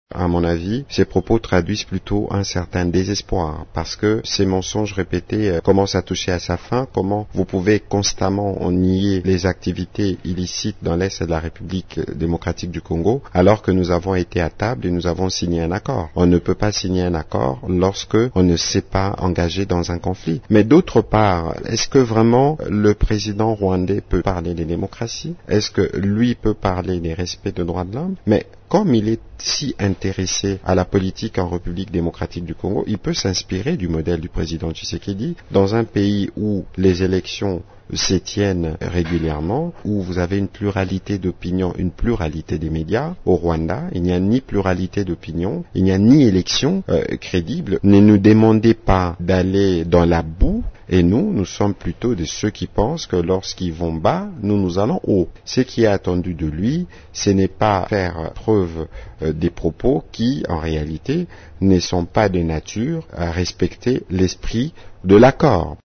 Le porte-parole du Gouvernement congolais, Patrick Muyaya, a réagi, samedi 5 juillet sur les ondes de Radio Okapi, a la récente sorti médiatique du président rwandais, Paul Kagame.
Vous pouvez suivre la réaction de Patrick Muyaya :